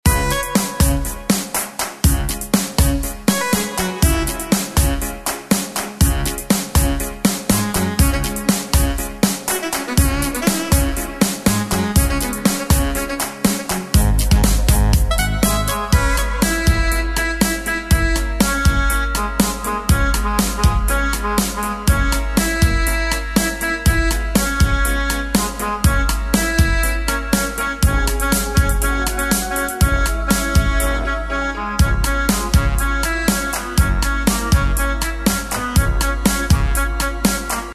Tempo: 121 BPM.
MP3 with melody DEMO 30s (0.5 MB)zdarma